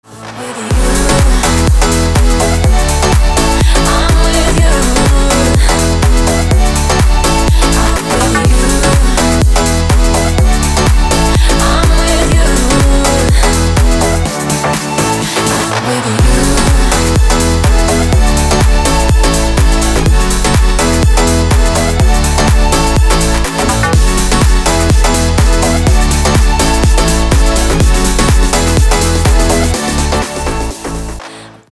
• Качество: 320, Stereo
громкие
женский вокал
dance
Electronic
EDM
электронная музыка
клавишные
Dance Pop